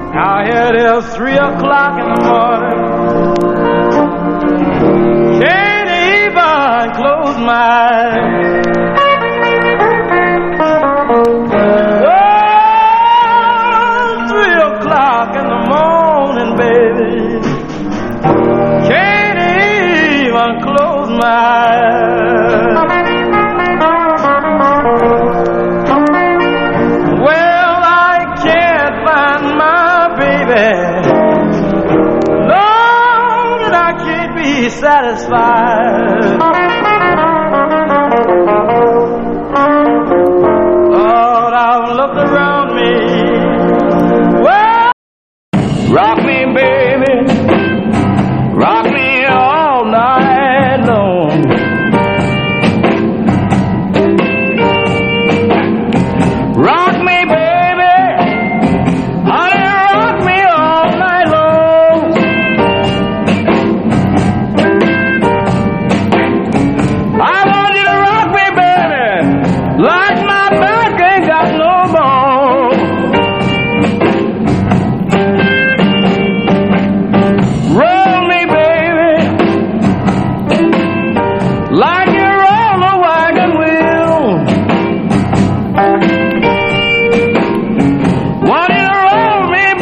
ファンキーなドラム入りのスクール・バンド！
B面には、構築的な吹奏楽曲を収録。